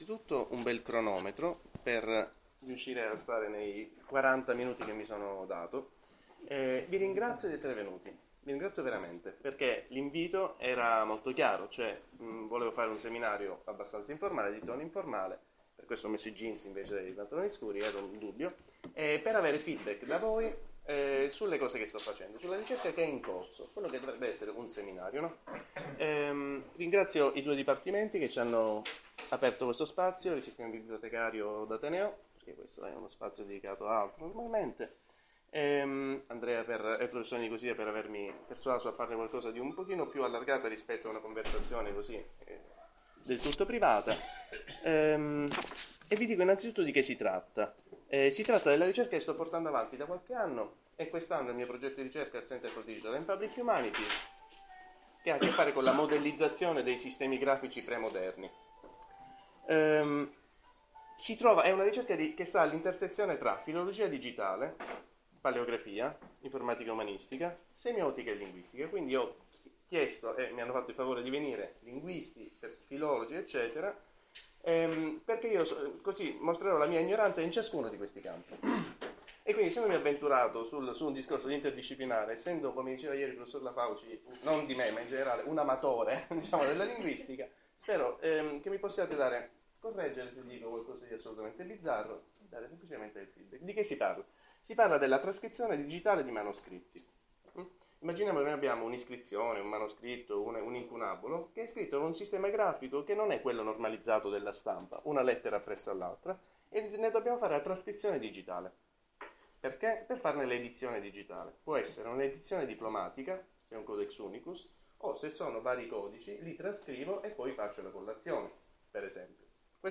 Dove Dipartimento Culture e Società Organizzazione Università degli Studi di Palermo
Registrazione audio del seminario